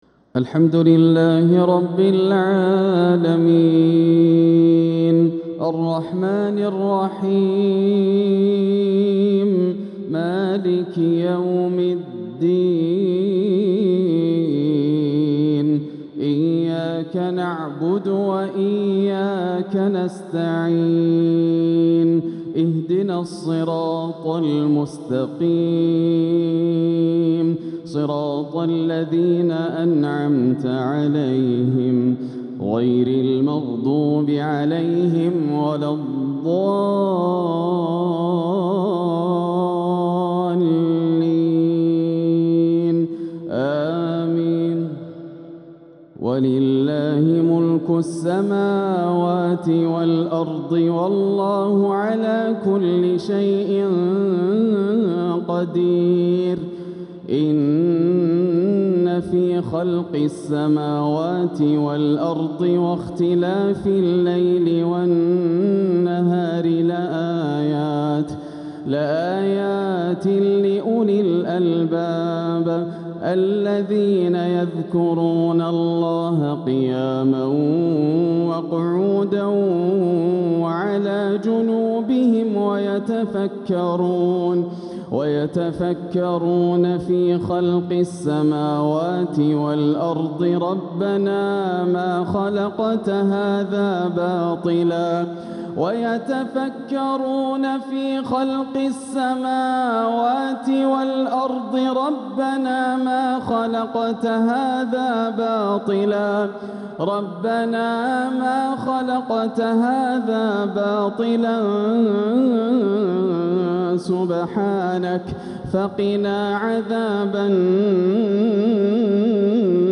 تلاوة من سورة آل عمران | مغرب السبت 5 شعبان 1447هـ > عام 1447 > الفروض - تلاوات ياسر الدوسري